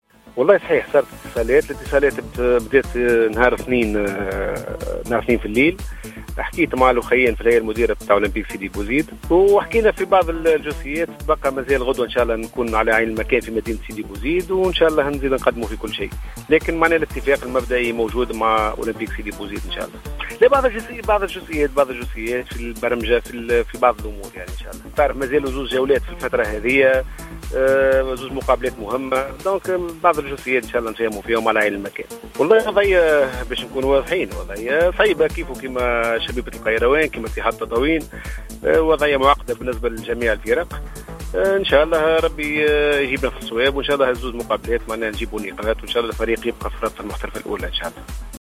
تصريح لراديو جوهرة أف أم